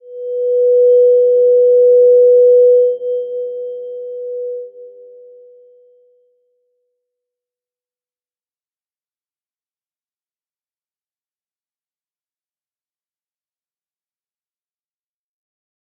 Slow-Distant-Chime-B4-p.wav